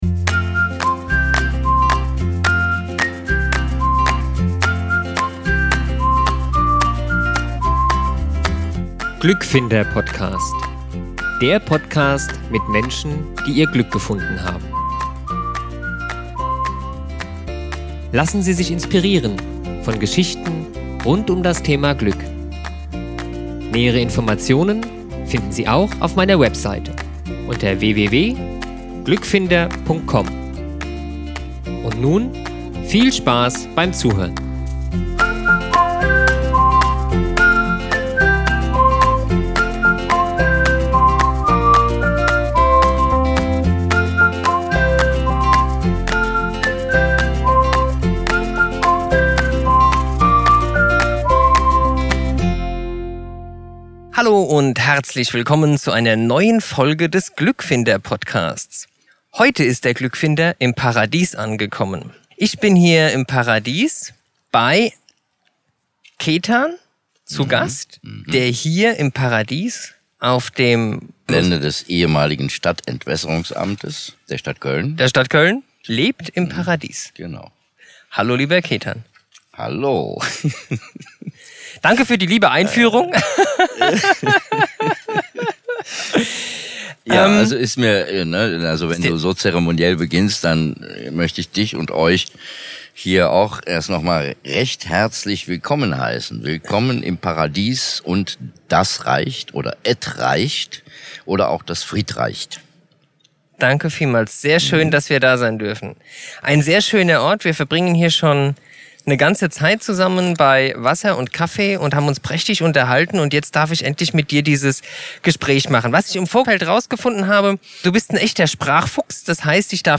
Inmitten der Großstadt treffe ich einen wunderbaren Menschen, dessen Geschichte mich zutiefst berührt hat.